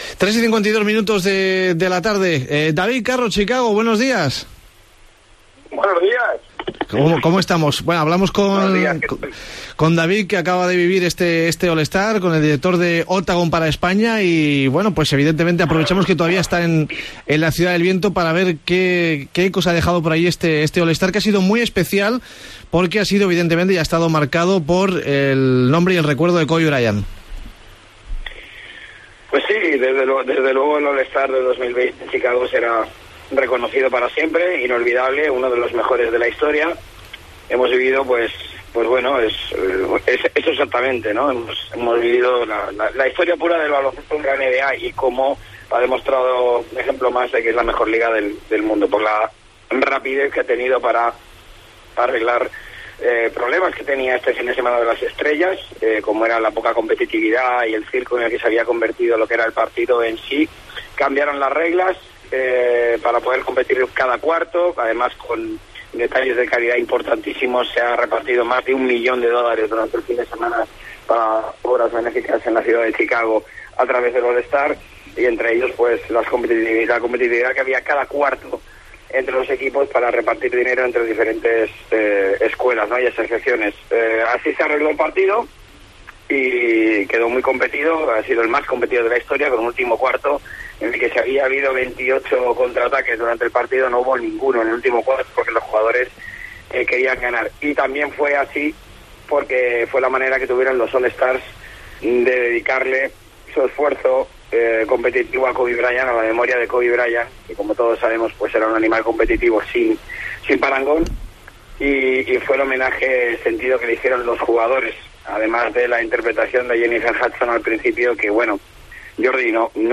Llamada a Chicago